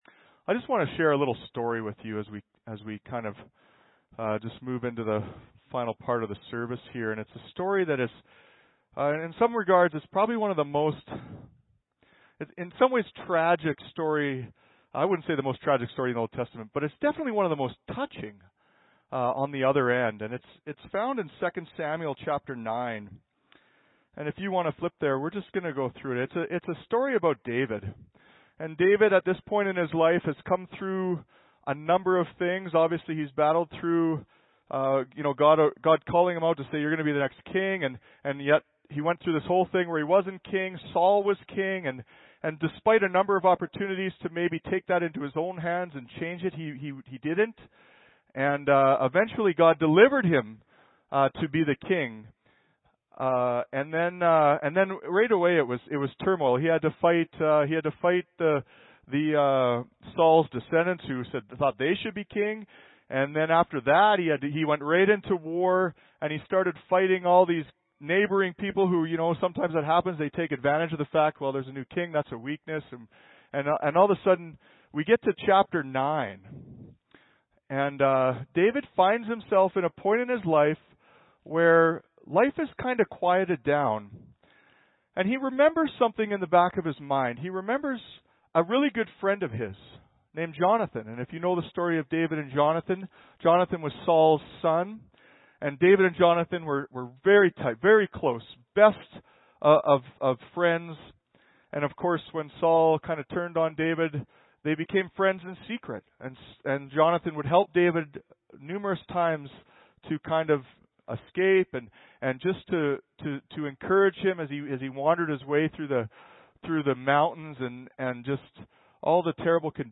Audio Sermon Library Worship by History.